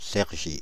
Cergy (French pronunciation: [sɛʁʒi]
Fr-Paris--Cergy.ogg.mp3